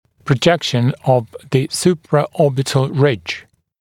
[prə’ʤekʃn əv ˌs(j)uːprə’ɔːbɪtl rɪʤ][прэ’джэкшн ов ˌс(й)у:прэ’о:битл ридж]выступ надбровной дуги